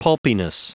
Prononciation du mot pulpiness en anglais (fichier audio)
pulpiness.wav